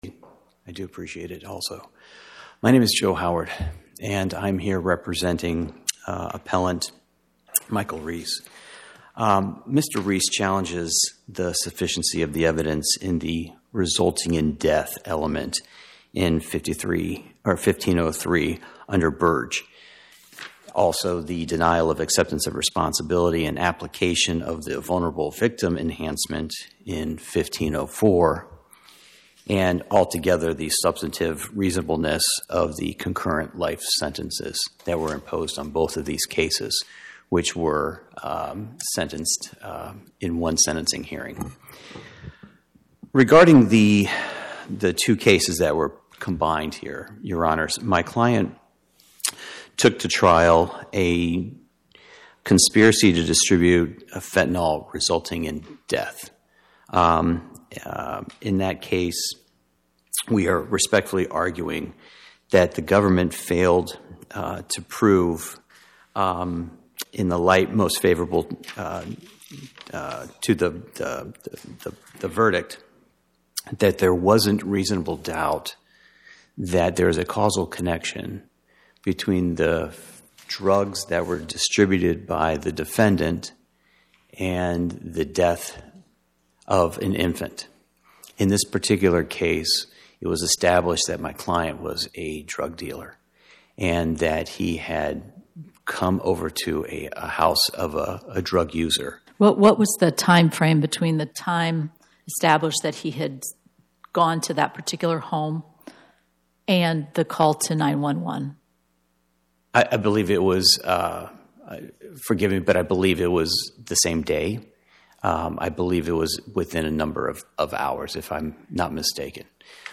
Oral argument argued before the Eighth Circuit U.S. Court of Appeals on or about 02/11/2026